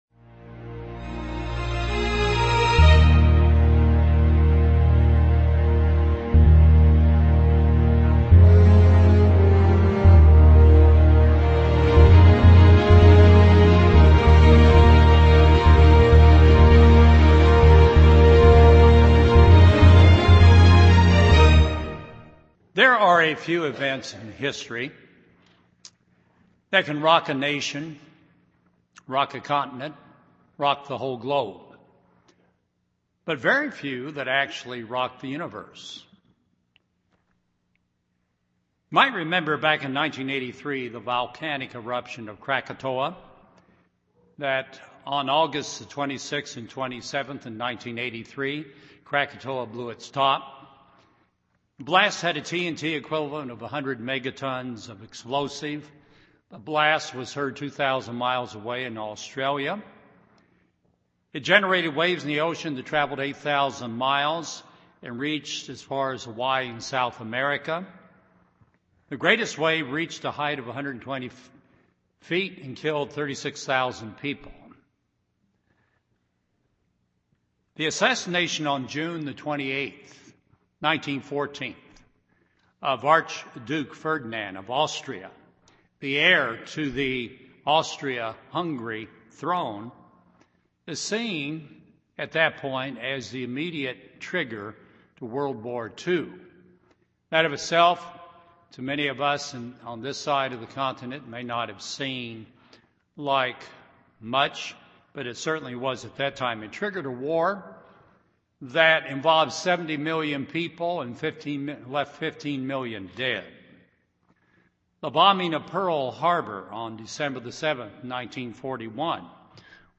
Ephesians 6:13 UCG Sermon Transcript This transcript was generated by AI and may contain errors.